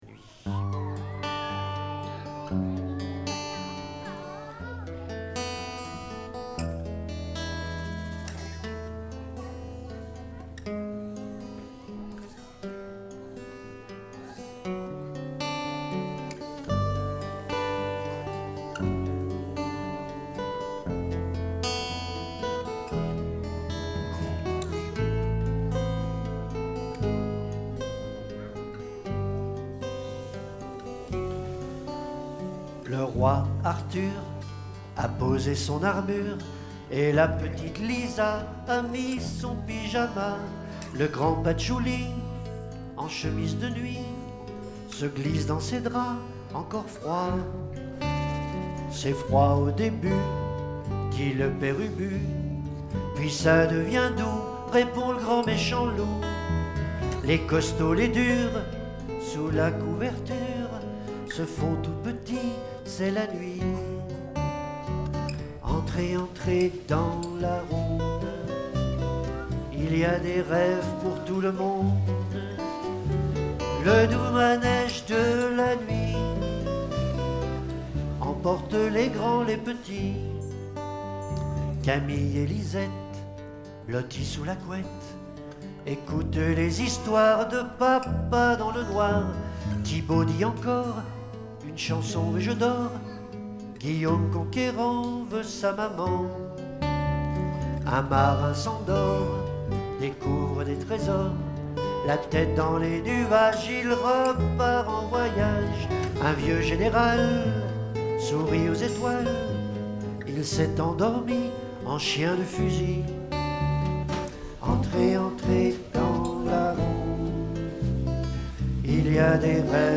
(formule « trio acoustique »)
Une formule conçue autour des guitares et des voix.
chant, guitares, harmo.
chant, guitares.